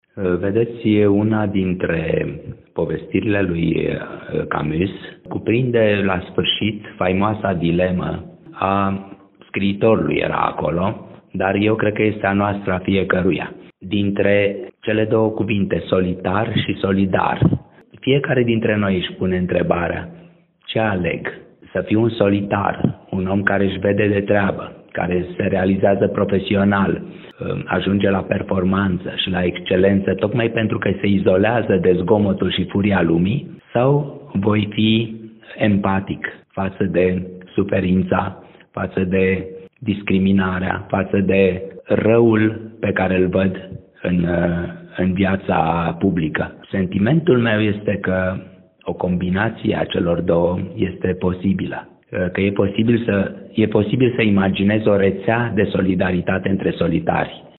? Mircea Cărtărescu a lansat, în premieră, la Timișoara “Peisaj după isterie”
Dacă cititorii vor căuta o temă definitorie a volumului Peisaj după isterie lansat în premieră la Timișoara, aceasta este sintetizată chiar de autor